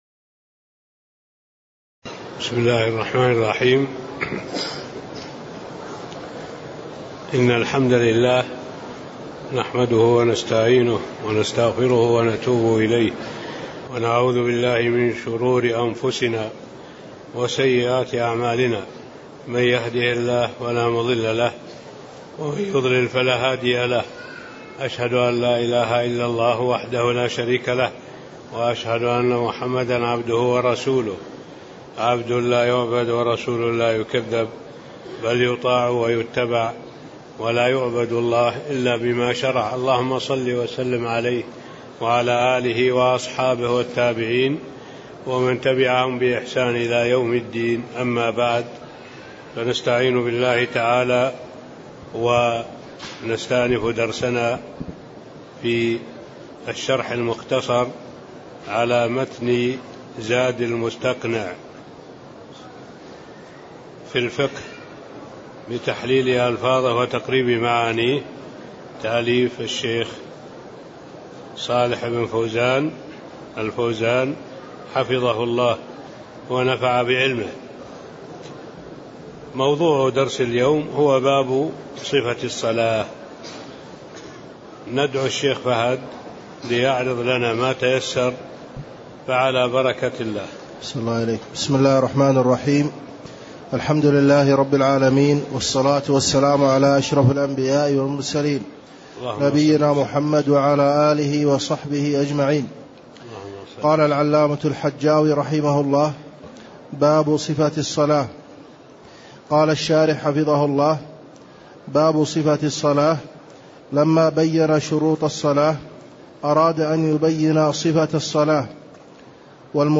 تاريخ النشر ١٨ جمادى الأولى ١٤٣٤ هـ المكان: المسجد النبوي الشيخ: معالي الشيخ الدكتور صالح بن عبد الله العبود معالي الشيخ الدكتور صالح بن عبد الله العبود باب صفة الصلاة (04)